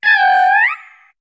Cri de Tutafeh dans Pokémon HOME.